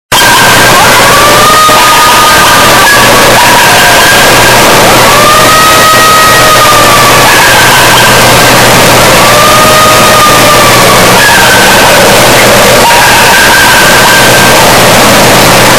A recording of a creature after it was fired upon in Westmoreland, Pennsylvania in 1973